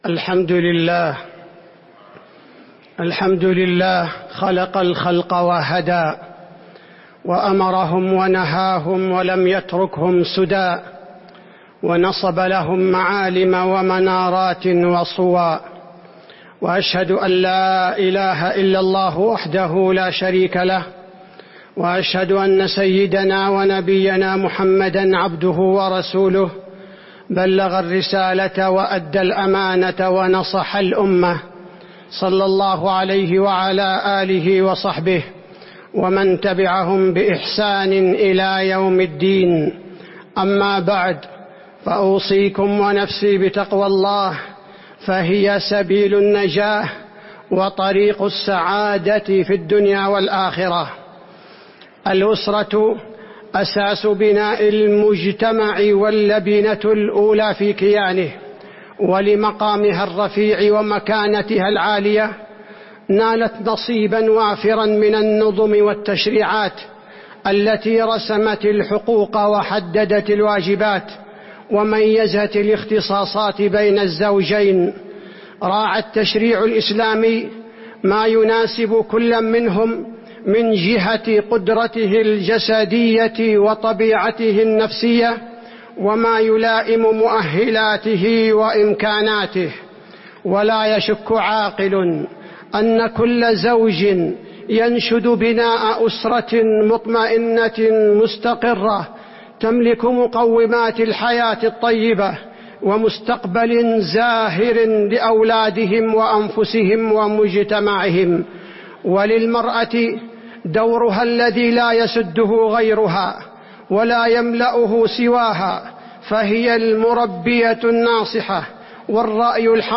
تاريخ النشر ٢٤ محرم ١٤٤٥ هـ المكان: المسجد النبوي الشيخ: فضيلة الشيخ عبدالباري الثبيتي فضيلة الشيخ عبدالباري الثبيتي القوامة الزوجية